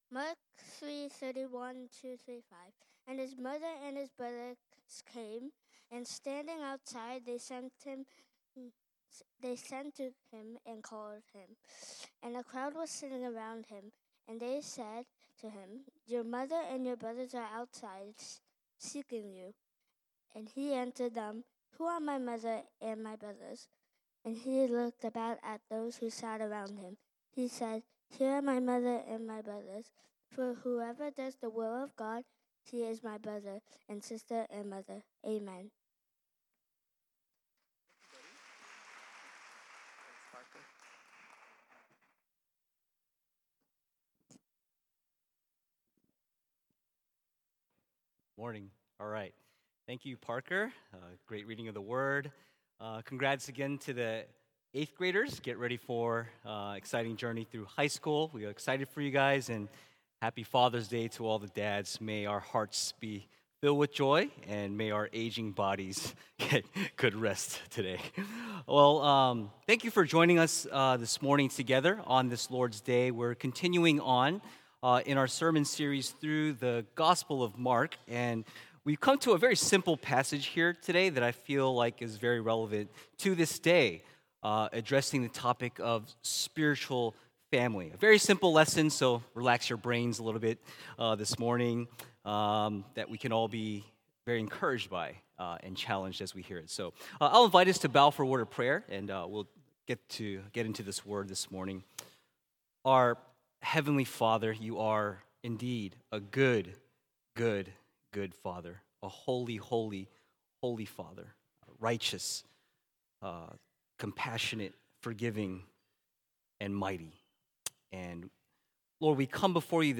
Weekly Sermons from Renewal Main Line